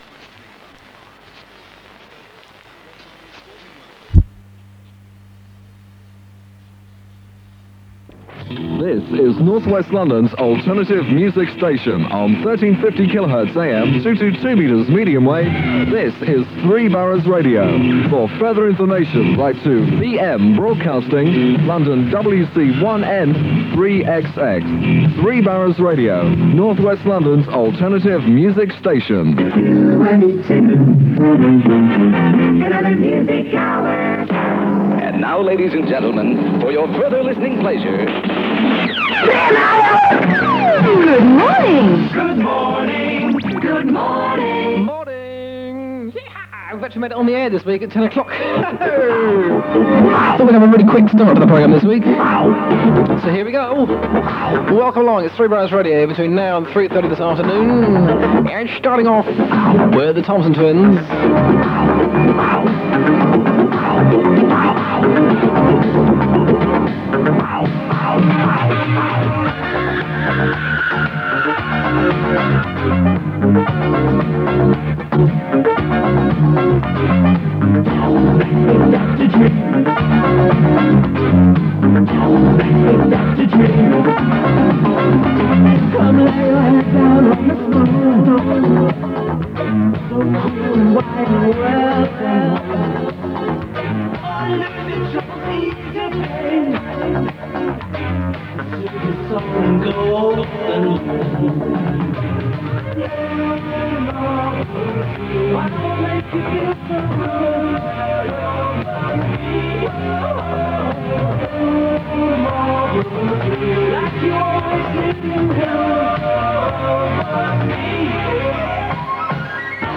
Nice signal and audio, and recorded from 1350kHz in Ickenham.
Recorded from 1350kHz in Ickenham.